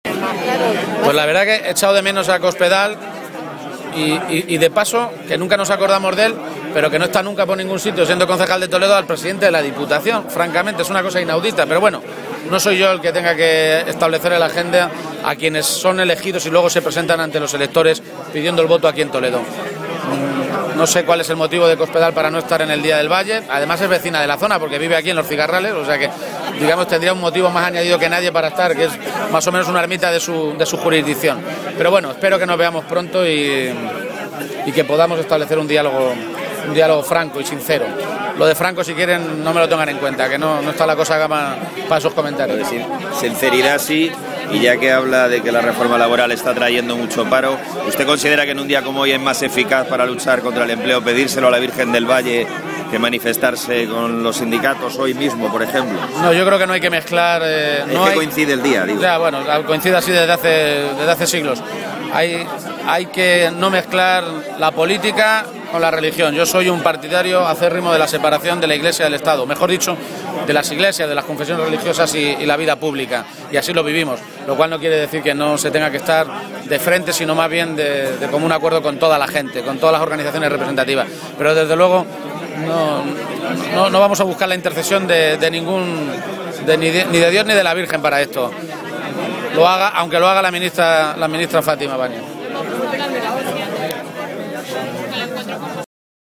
Page se pronunciaba así, esta mañana, en la tradicional Romería del Valle de Toledo, a preguntas de los medios de comunicación.
Cortes de audio de la rueda de prensa